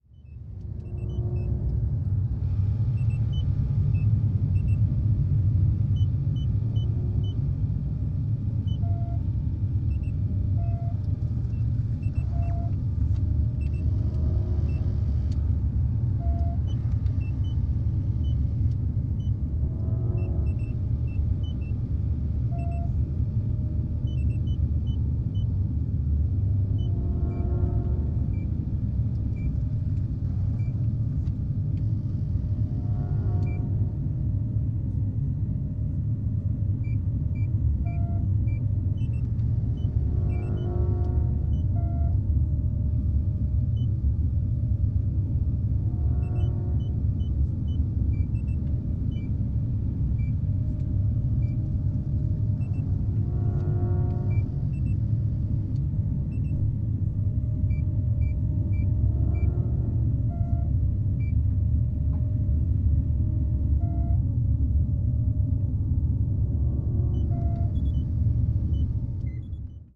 Звук работающих приборов внутри кабины космического корабля (атмосферный звук) (01:10)
атмосферный звук